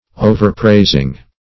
overpraising - definition of overpraising - synonyms, pronunciation, spelling from Free Dictionary
Search Result for " overpraising" : The Collaborative International Dictionary of English v.0.48: Overpraising \O`ver*prais"ing\, n. The act of praising unduly; excessive praise.